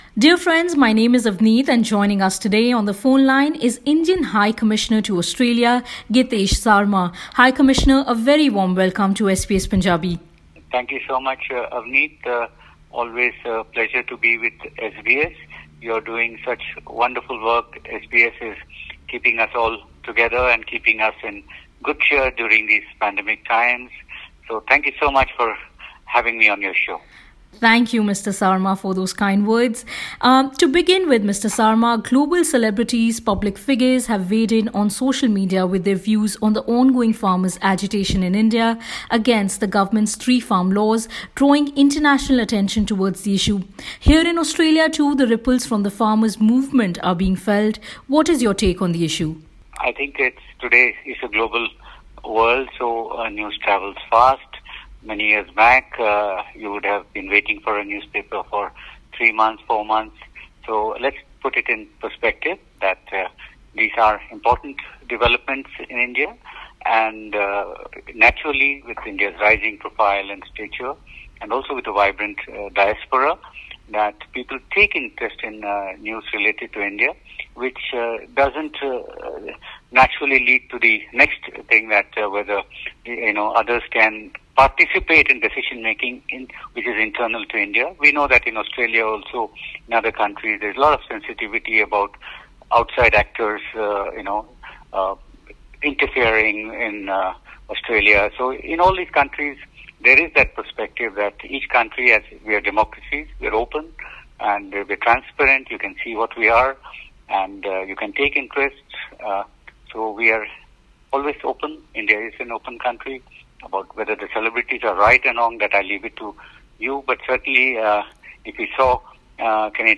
In an interview with SBS Punjabi, India's High Commissioner to Australia Gitesh Sarma talks about the ongoing farmers’ agitation in India and addresses the concerns of temporary visa holders from his home country, including international students stuck outside Australia.
interview_with_high_commissioner.mp3